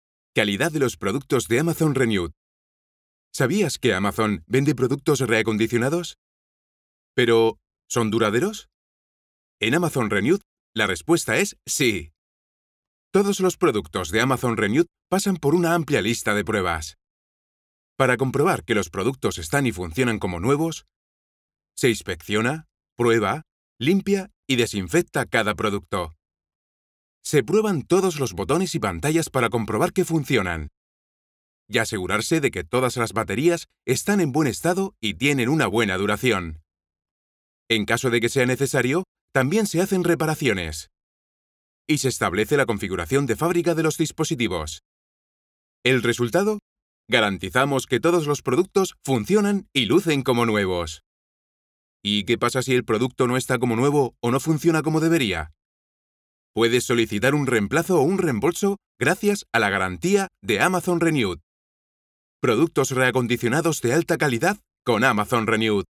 Professional advertising and documentary announcer, with more than 20 years of experience.
Sprechprobe: Sonstiges (Muttersprache):
Voice Actor for commercials radio, spots tv, dubbing and documentaries
Amazon-TV spot_SPA-EU.mp3